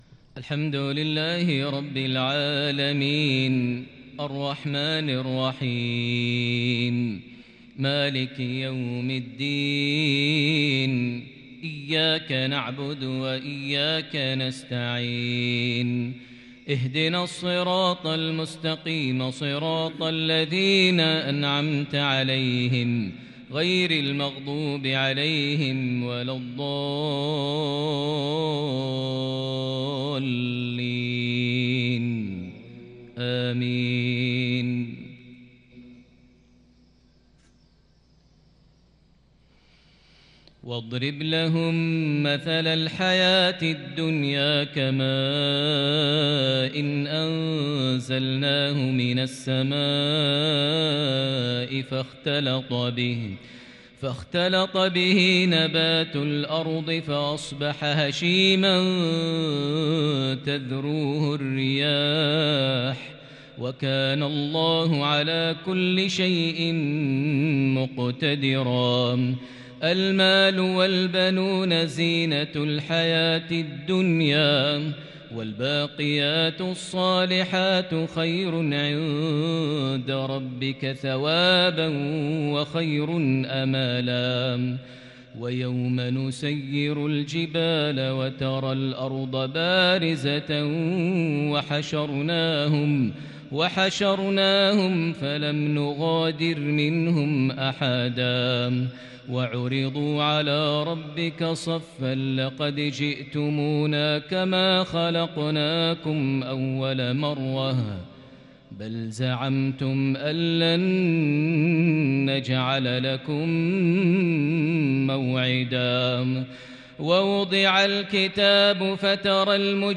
(وكان الإنسان أكثر شيء جدلاً) مغربية فريدة بالكرد من سورة الكهف (45-54) | 10 جمادى الأول 1442هـ > 1442 هـ > الفروض - تلاوات ماهر المعيقلي